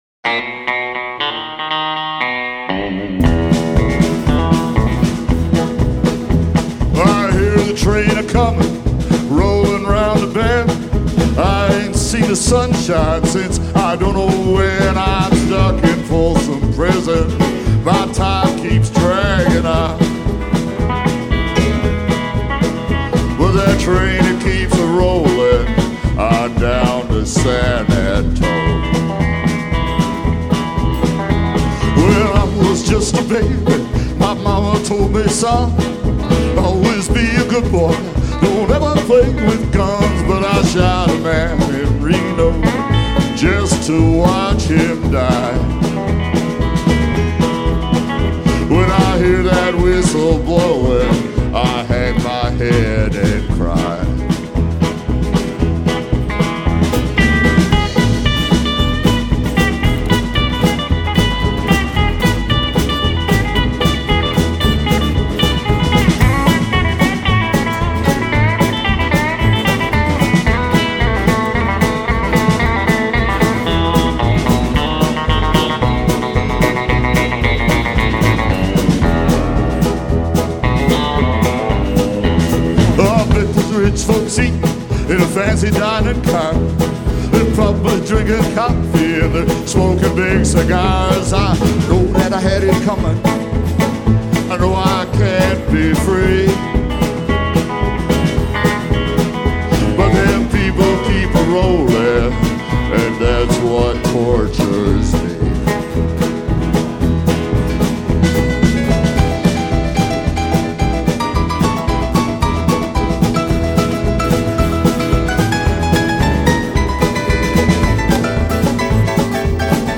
live album
The album was recorded on June 24th, 2023 in Bonavista, NL.
Guitars, Harmonica, Vocals
Vocal & Keys
Banjo & Vocals
Drums & Vocals
Bass & Vocals